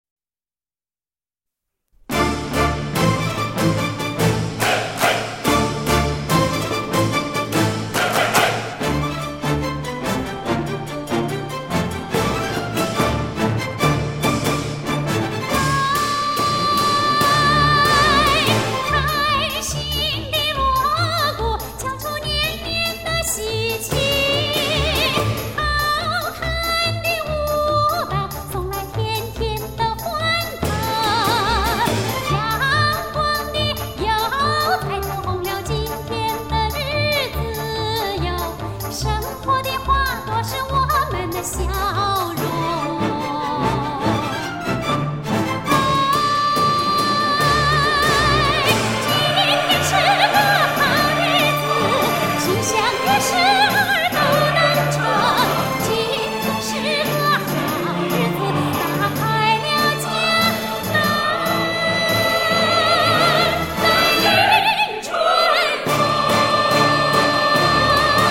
中国で最も美しい民歌歌手(湖南出身)，国家１級演員。